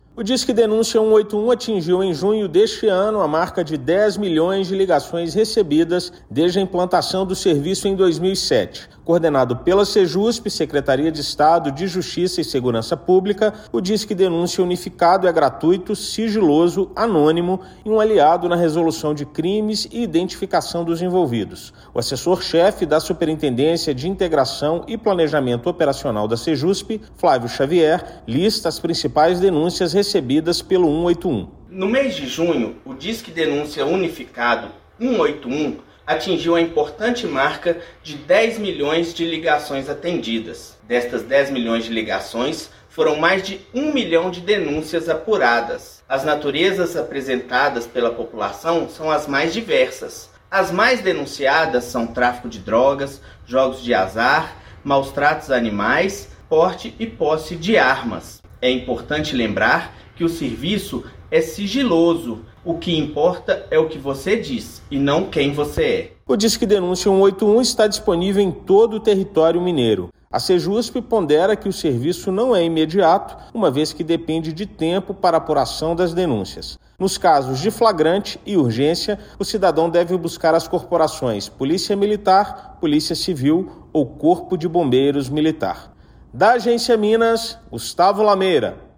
Canal pode ser utilizado por todos os mineiros, com anonimato e sigilo assegurados. Ouça matéria de rádio.